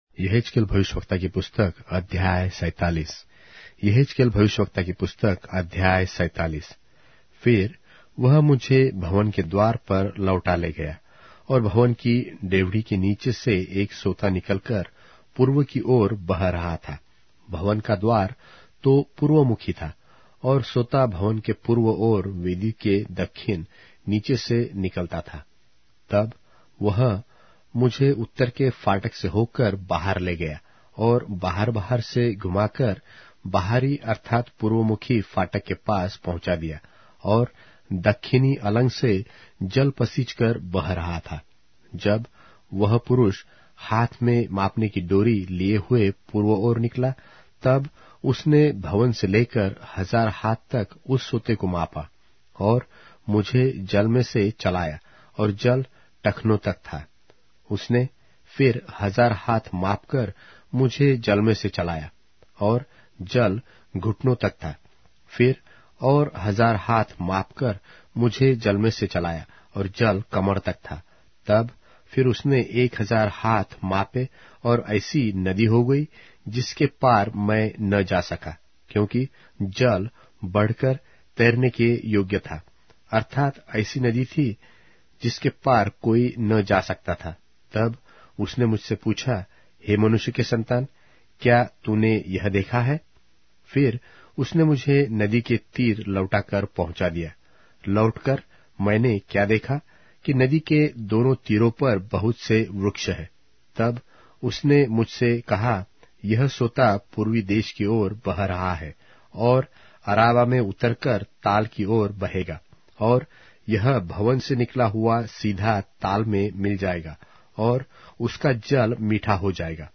Hindi Audio Bible - Ezekiel 1 in Irvte bible version